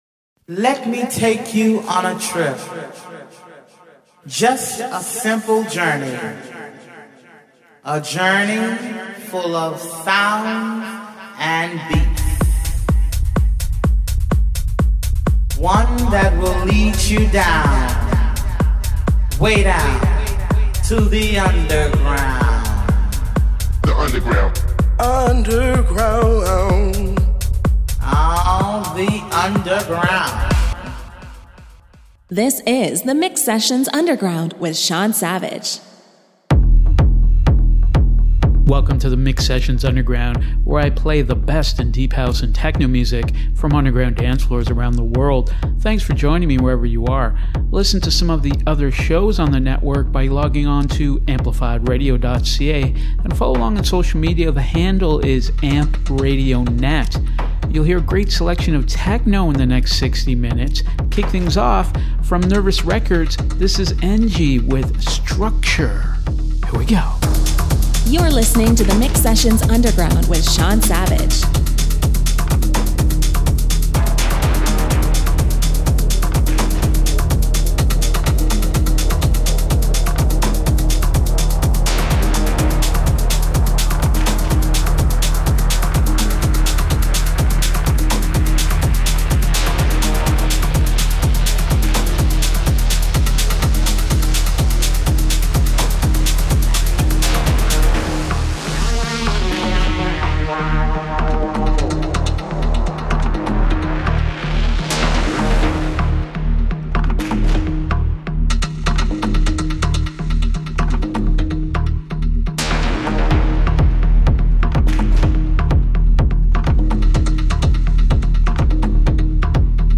Underground Deep House and Techno